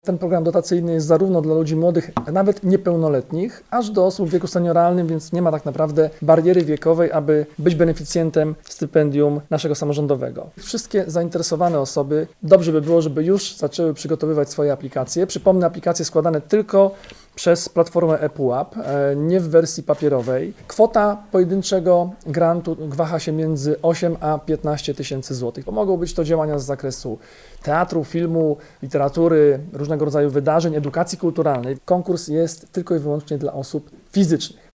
Jarosław Rabczenko, członek Zarządu Województwa Dolnośląskiego dodaje, że samorząd wychodzi naprzeciw potrzebom twórców w różnym wieku. Stypendia mogą otrzymać zarówno osoby niepełnoletnie, jak i seniorzy.